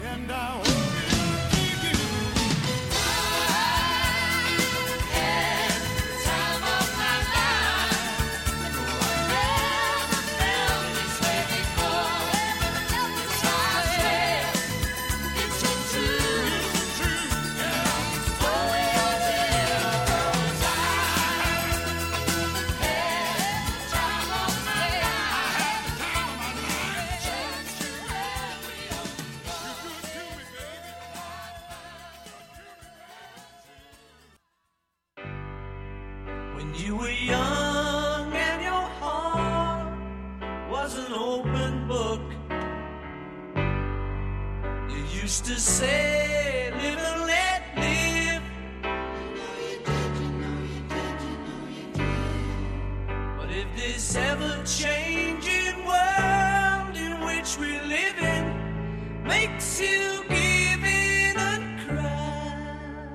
Descripció Música